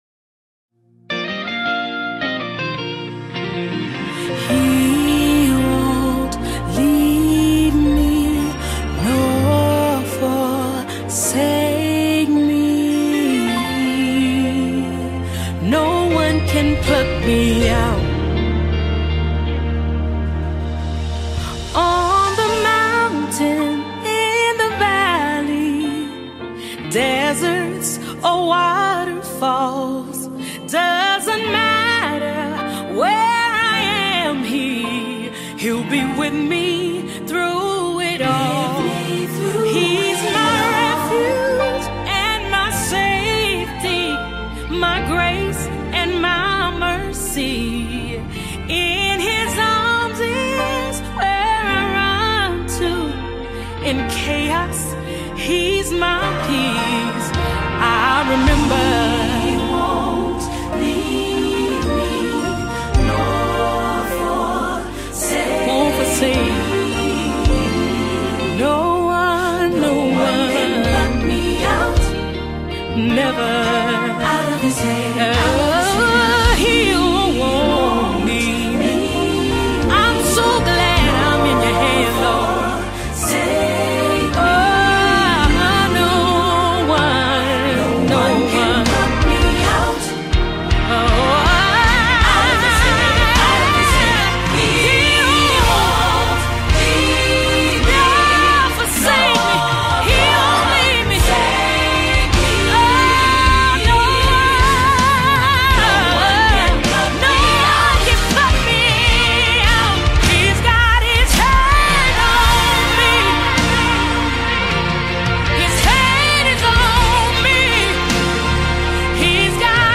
Mp3 Gospel Songs
heartfelt ballad